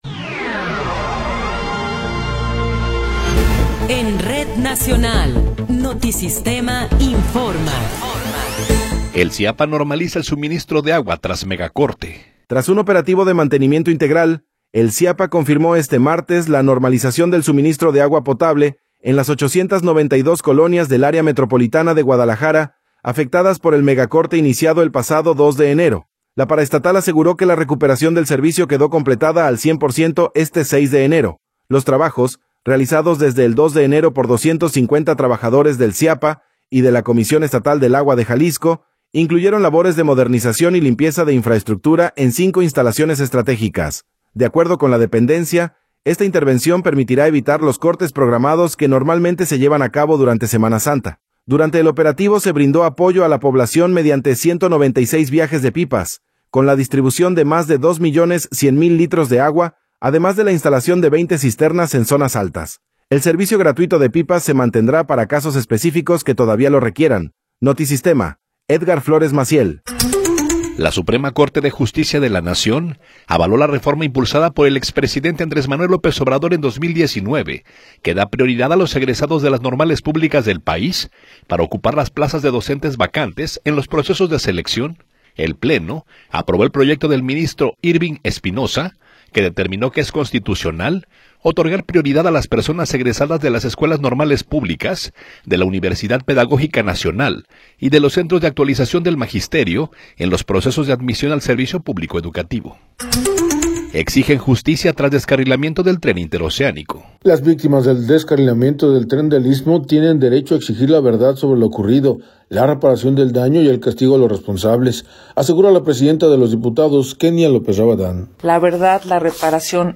Noticiero 17 hrs. – 6 de Enero de 2026
Resumen informativo Notisistema, la mejor y más completa información cada hora en la hora.